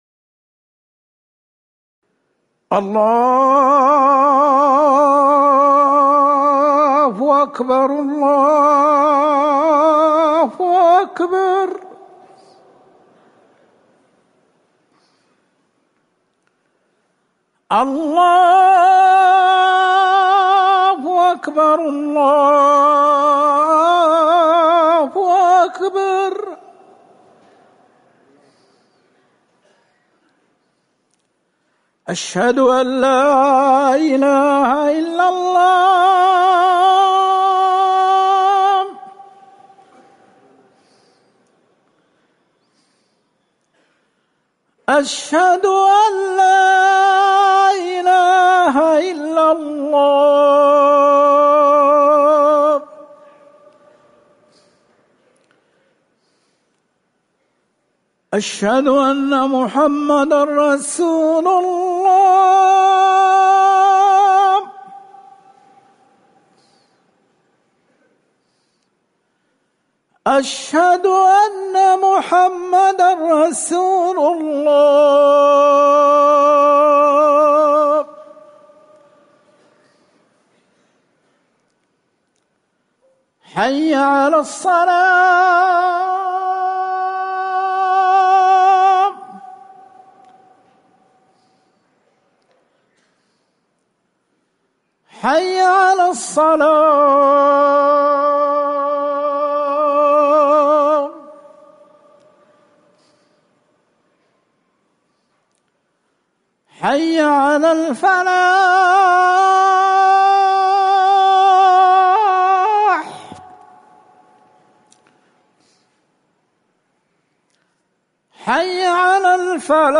أذان العصر - الموقع الرسمي لرئاسة الشؤون الدينية بالمسجد النبوي والمسجد الحرام
تاريخ النشر ١٨ محرم ١٤٤١ هـ المكان: المسجد النبوي الشيخ